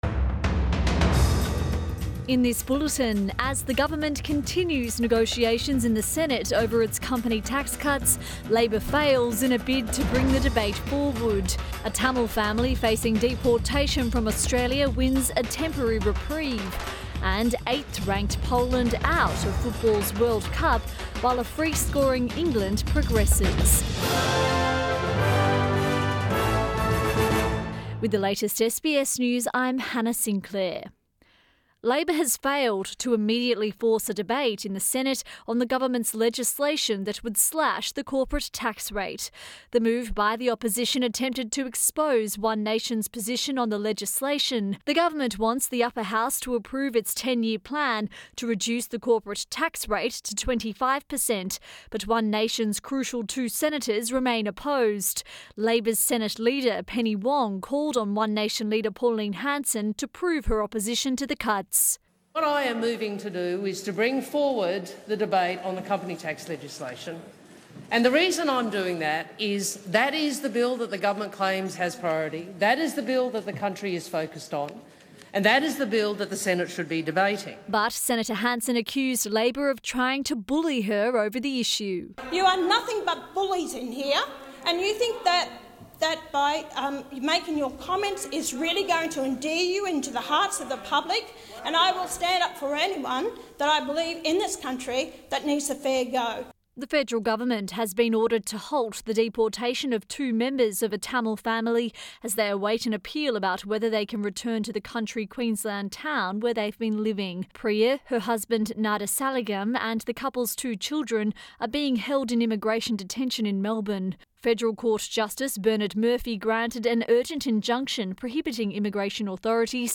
PM bulletin 25 June